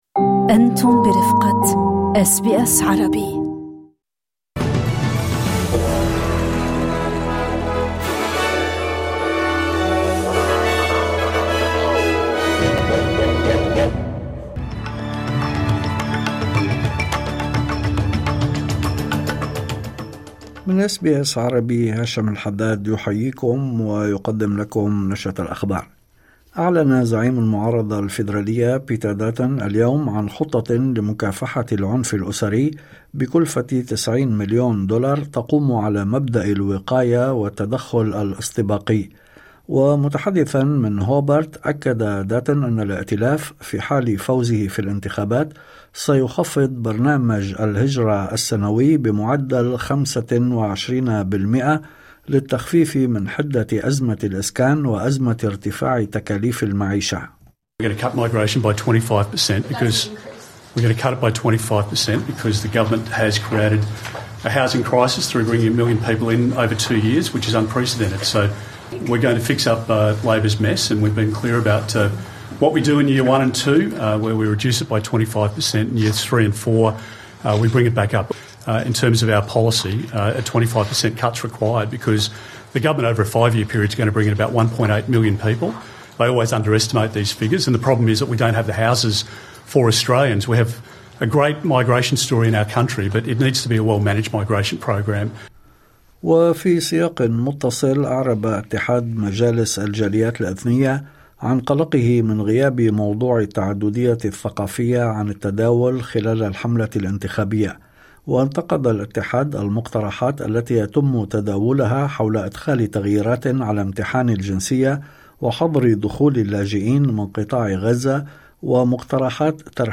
نشرة أخبار الظهيرة 24/04/2025